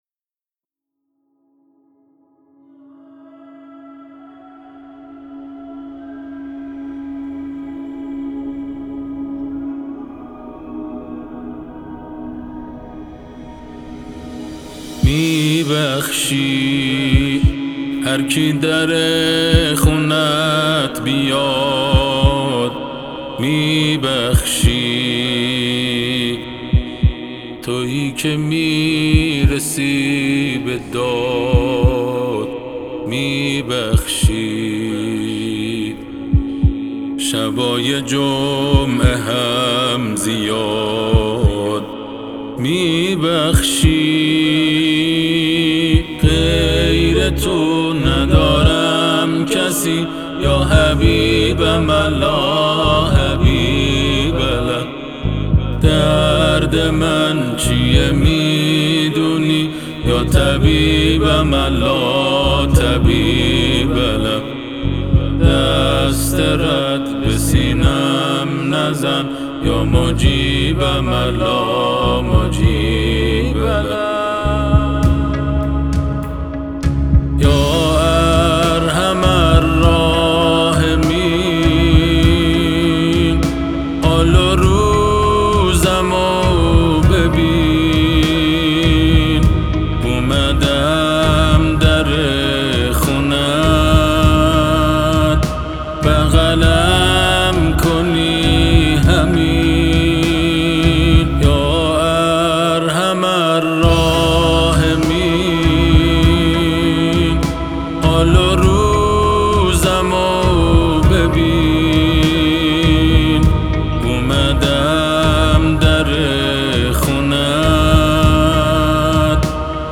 آثار آهنگین مذهبی
تواشیح